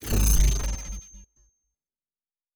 pgs/Assets/Audio/Sci-Fi Sounds/Electric/Device 8 Stop.wav at master
Device 8 Stop.wav